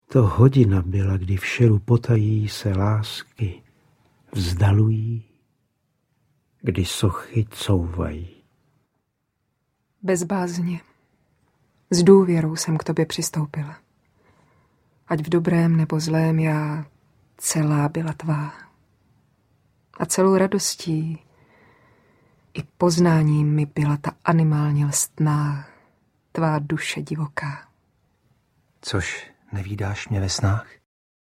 Byla pastýřka malá audiokniha
byla-pastyrka-mala-audiokniha